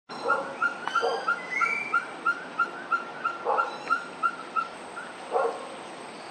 Ferruginous Pygmy Owl (Glaucidium brasilianum)
Life Stage: Adult
Location or protected area: Playa del Carmén
Condition: Wild
Certainty: Photographed, Recorded vocal